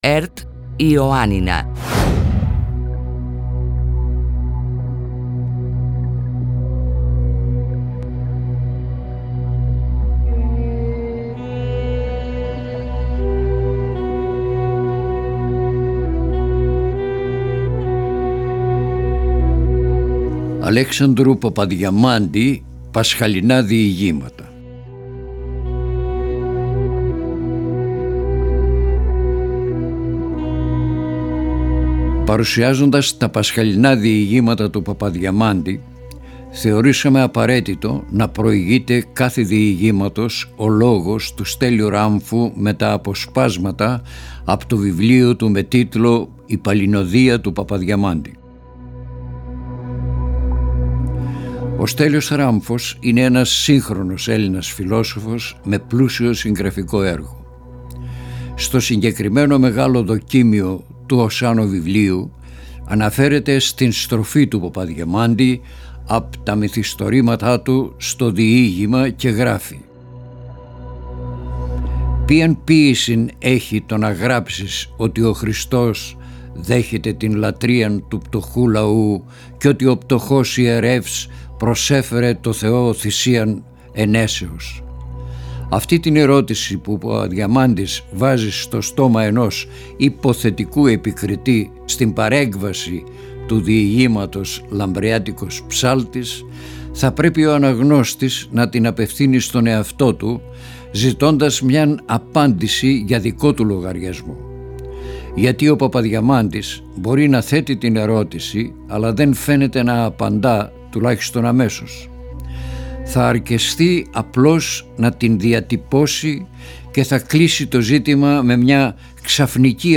αφηγείται το διήγημα του Αλέξανδρου Παπαδιαμάντη «Τ’ αερικό στο δέντρο».